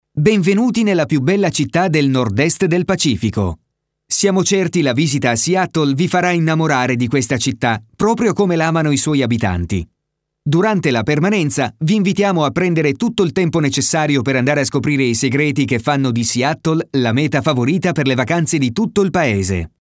Voce giovane, adatta a comunicati dinamici, che richiedono un forte impatto energetico oppure per comunicati capaci di attirare l'attenzione con un messaggio chiaro, profondo e convincente.
Sprechprobe: Sonstiges (Muttersprache):
A new and fresh voice for your commercial, promos and any more!